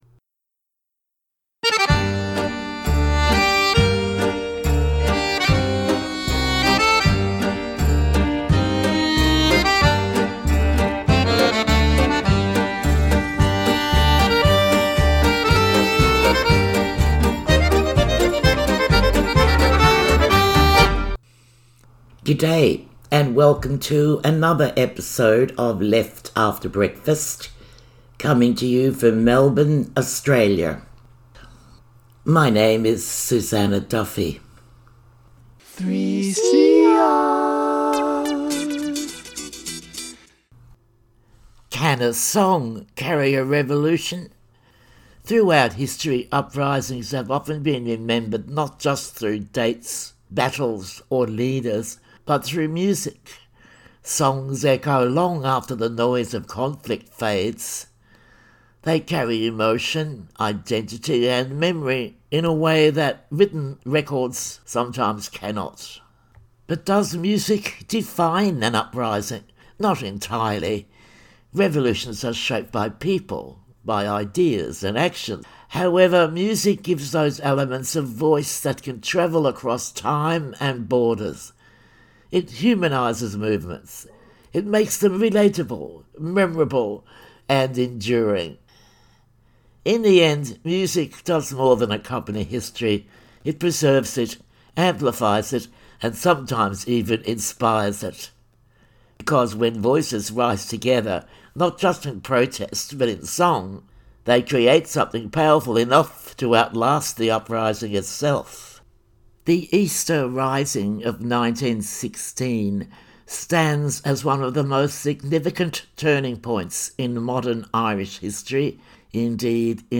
Women talk about their experiences in 1980s and 70s.